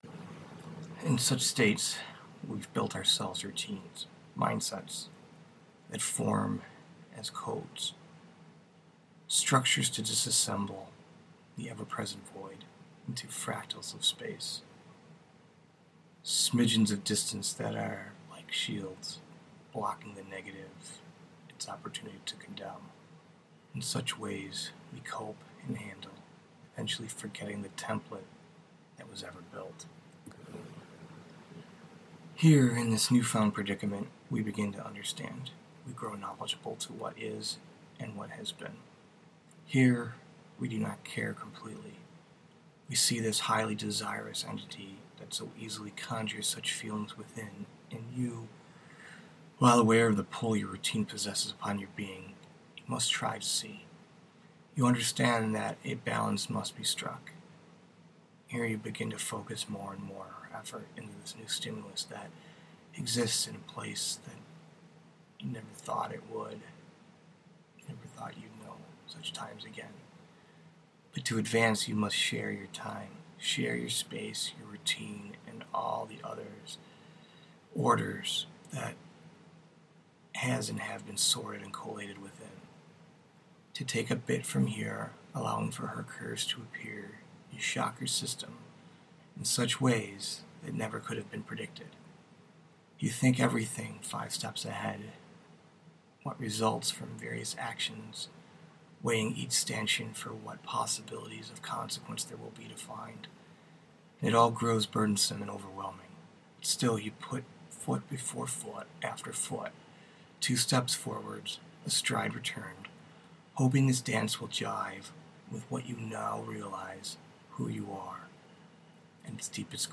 Theory, Philosophy, Prose, Reading